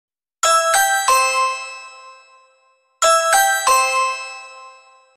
Ring Doorbell Sound Effect Free Download
Ring Doorbell